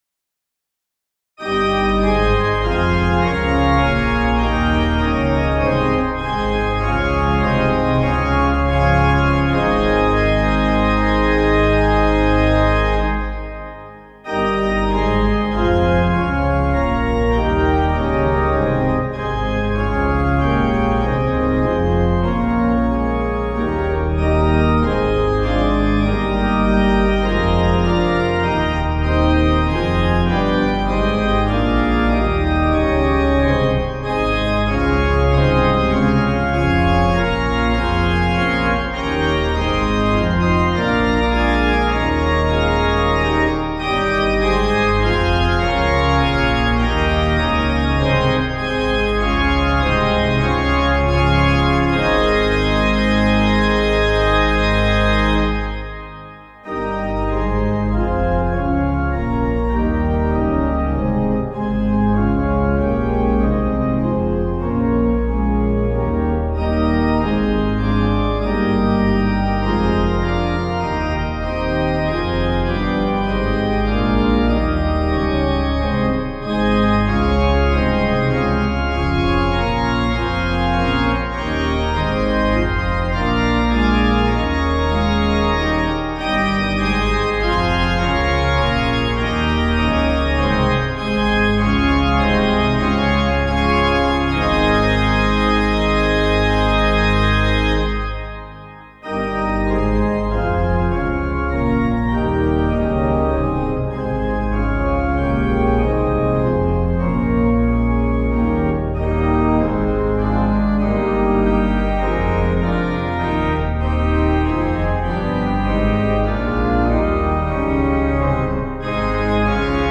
Organ
(CM)   4/Ab 477.2kb